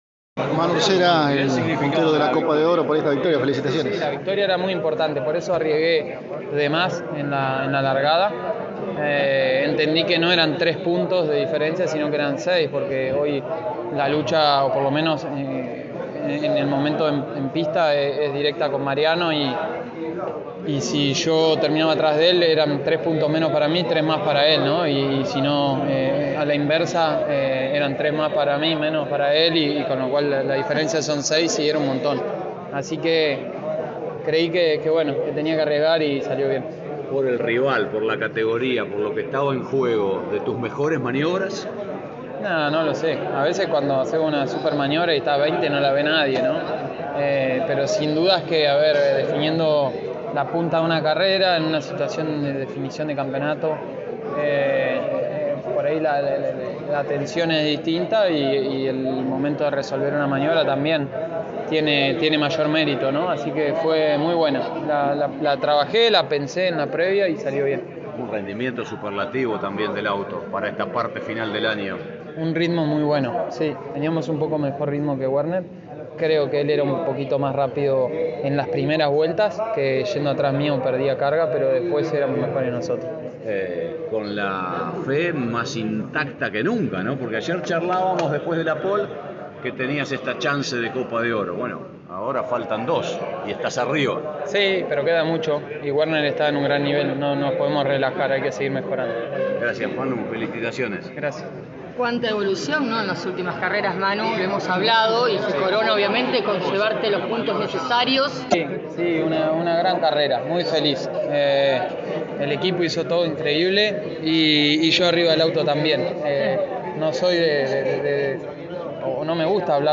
A continuación, escuchamos la palabra del ganador, José Manuel Urcera: